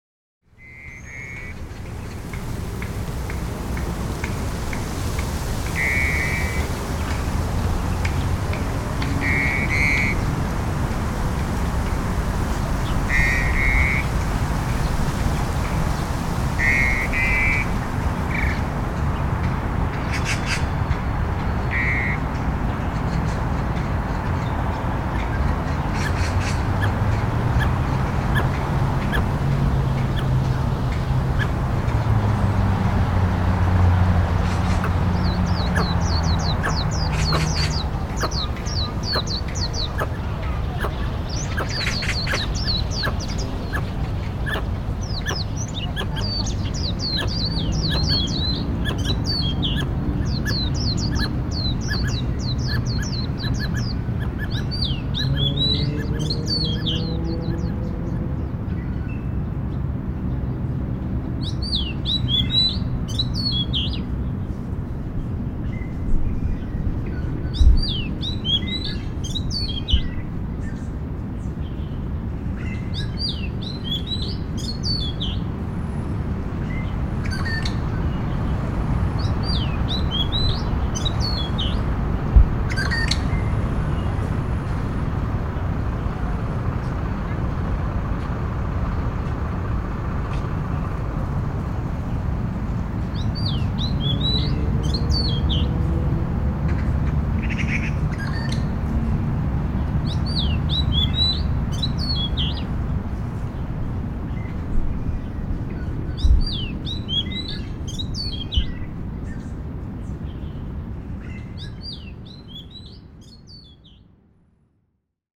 1-大安森林公園 | 台灣聲景協會 Soundscape Association of Taiwan
1-大安森林公園.mp3